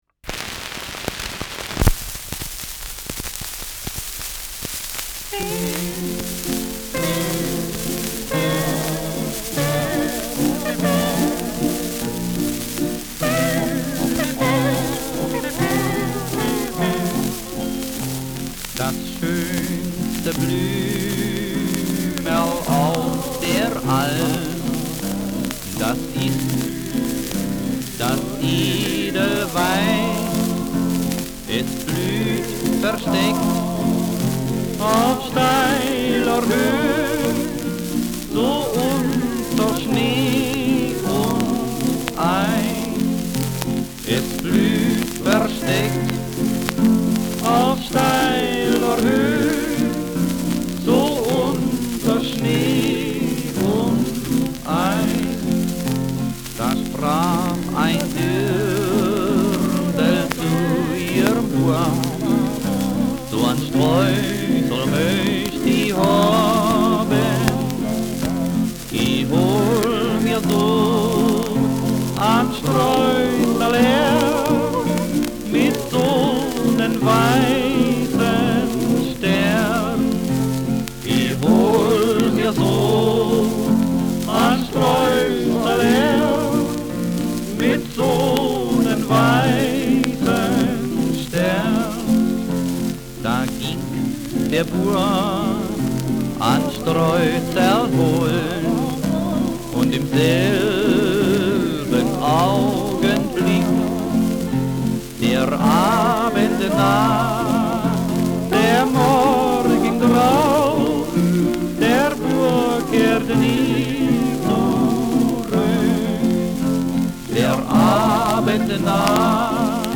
Schellackplatte
Abgespielt : Nadelgeräusch : Gelegentlich stärkeres Knacken
Vier Wedding-Boys (Interpretation)
Extreme Anwendung der Slide-Technik beim begleitenden Saiteninstrument.